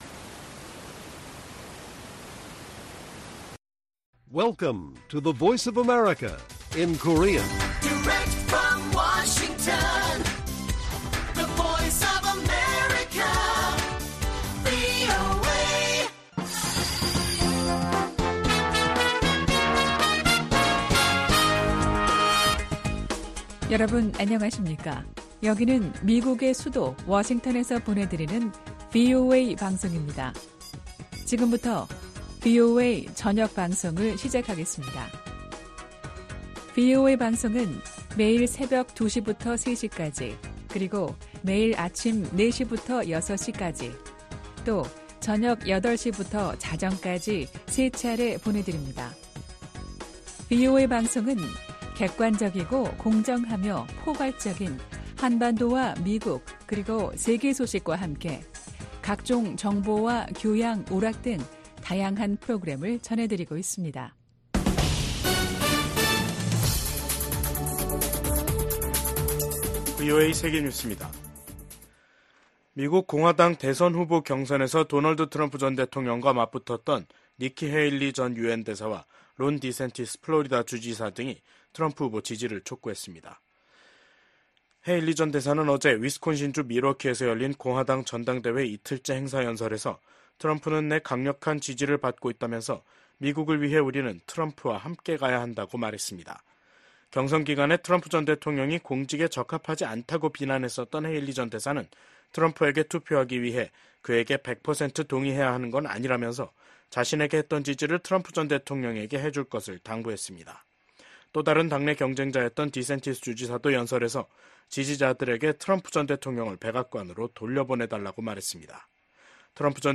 VOA 한국어 간판 뉴스 프로그램 '뉴스 투데이', 2024년 7월 17일 1부 방송입니다. 미 국방부의 고위 관리가 VOA와의 단독 인터뷰에서 미한 양국은 핵을 기반으로 한 동맹이라고 밝혔습니다. 북한의 엘리트 계층인 외교관들의 한국 망명이 이어지고 있습니다. 북한에서 강제노동이 광범위하게 제도화돼 있으며 일부는 반인도 범죄인 노예화에 해당할 수 있다고 유엔이 지적했습니다.